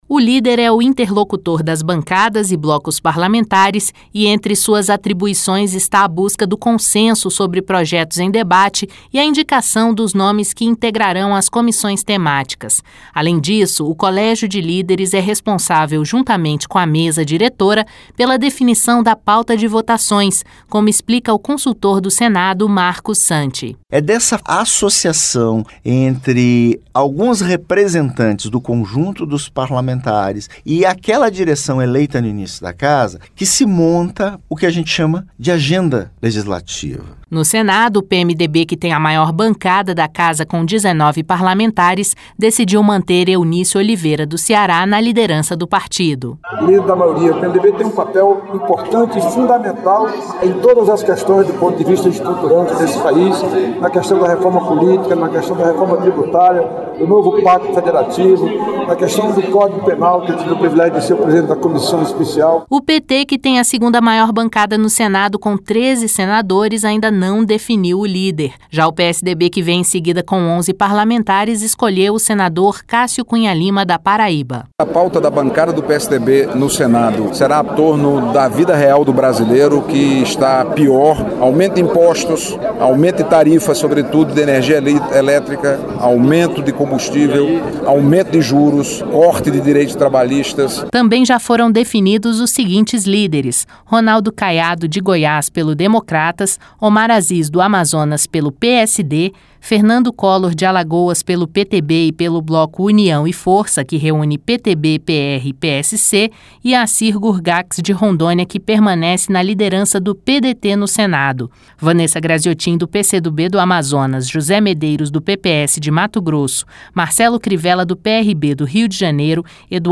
COMO INFORMA A REPÓRTER